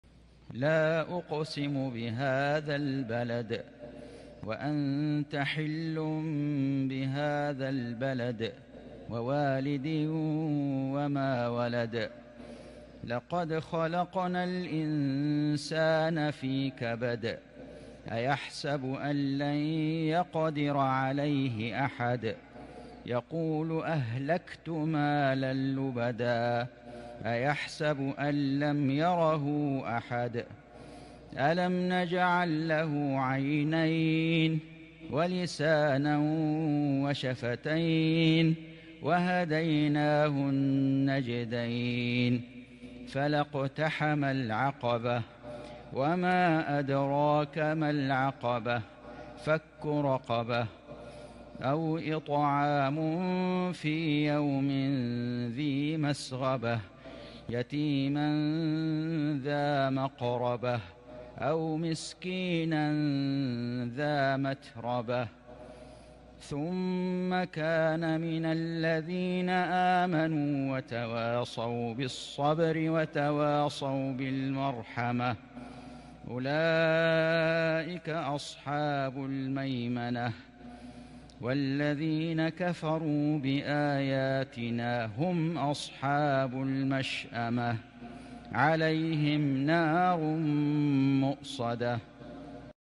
سورة البلد > السور المكتملة للشيخ فيصل غزاوي من الحرم المكي 🕋 > السور المكتملة 🕋 > المزيد - تلاوات الحرمين